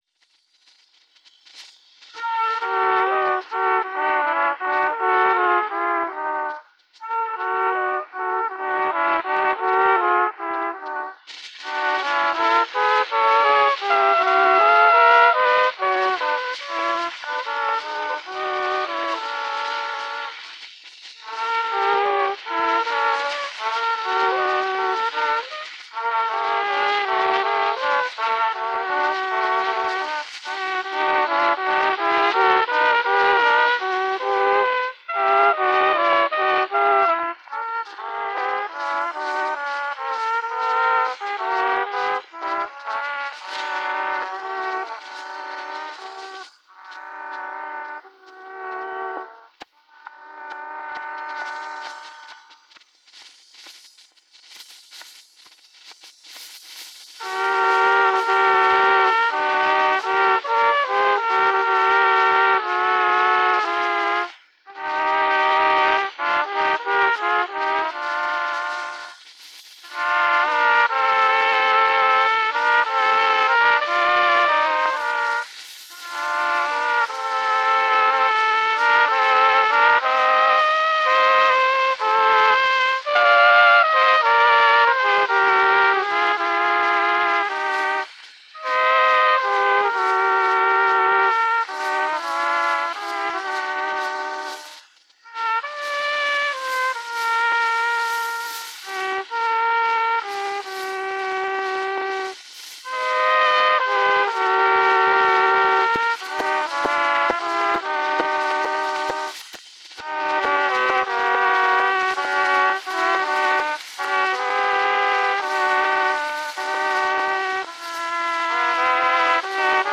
[To duet-kompositioner spillet på kornet og trompet]
To duet-kompositioner spillet af kornet og trompet. Kun første komposition er komplet.